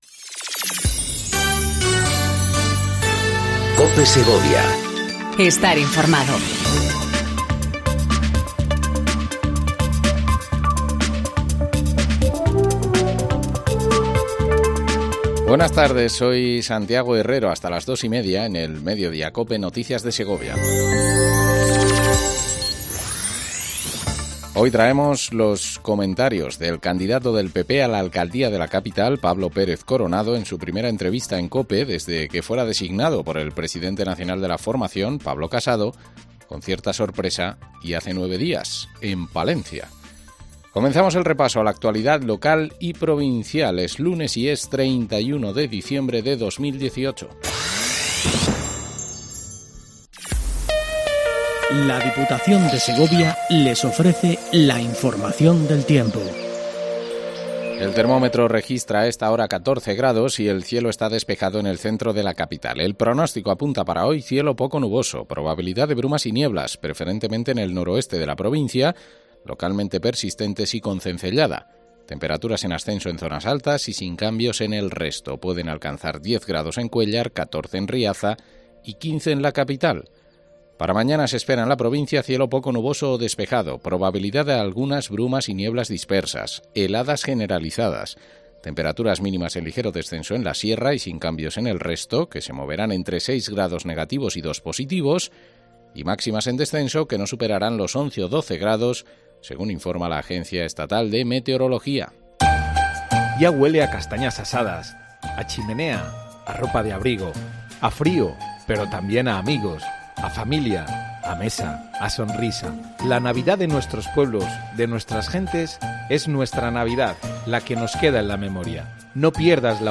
INFORMATIVO DE MEDIODÍA EN COPE SEGOVIA 14:20 DEL 31/12/18